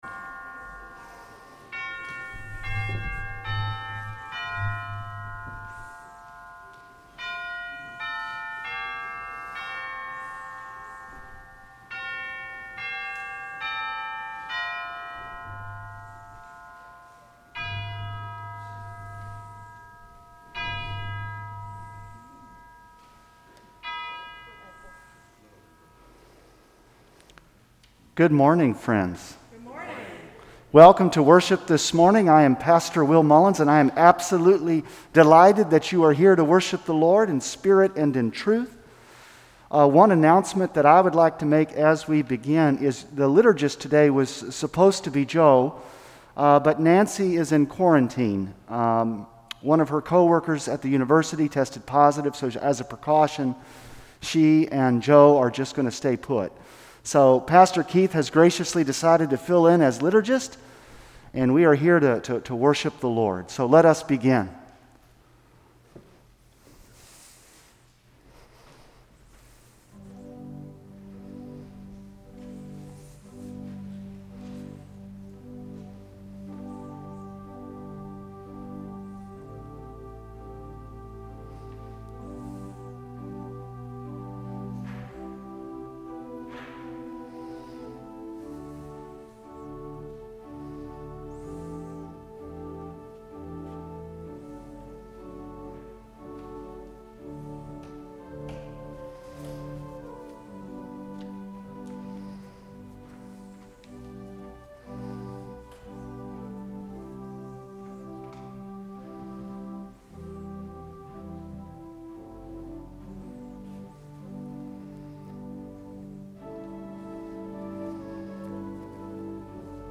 Please click below for audio recording of this worship service.